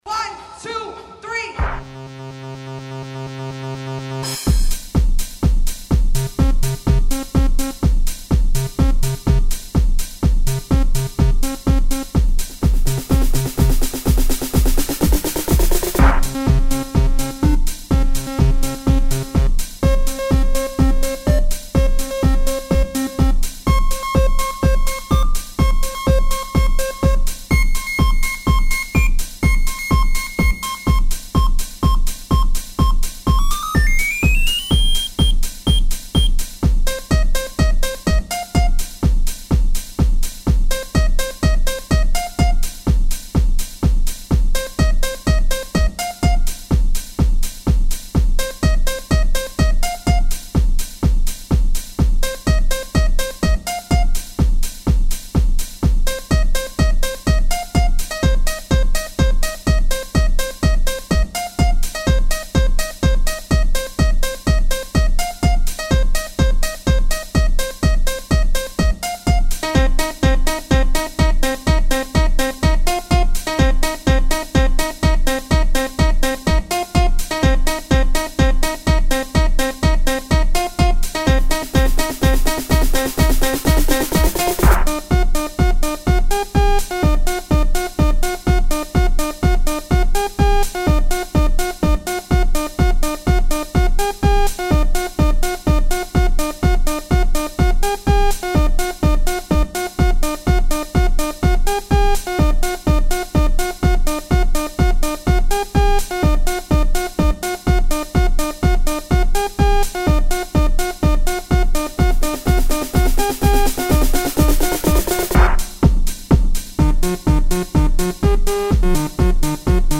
Voici une musique que j'ai faite avec MODPlug, bon, ce n'est pas du grand art mais c'est pour vous donner une idée de ce que l'on peut faire avec ce logiciel: